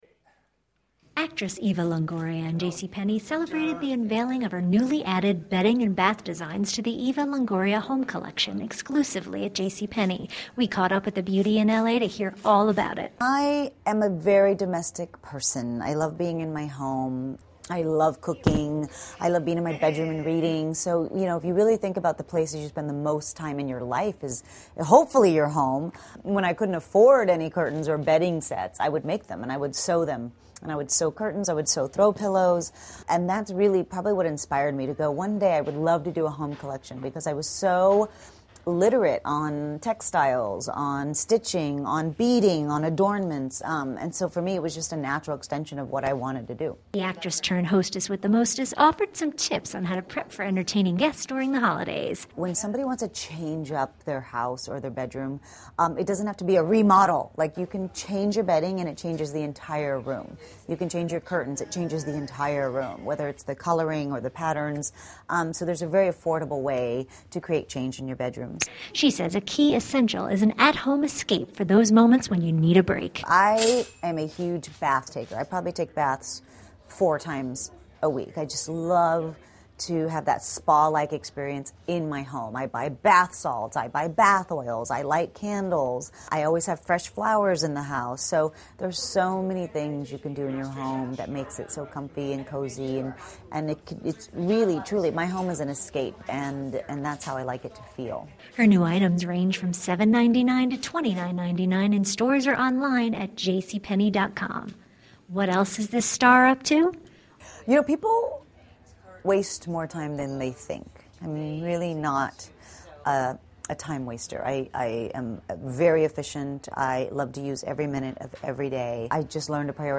Actress Eva Longoria and JCPenney celebrated the unveiling of her newly added bedding and bath designs to the Eva Longoria Home Collection exclusively at JCPenney. We caught up with the beauty in LA to hear all about it.